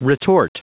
Prononciation du mot retort en anglais (fichier audio)
Prononciation du mot : retort